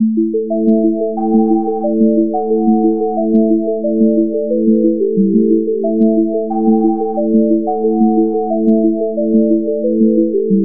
合成器循环。